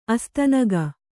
♪ astanaga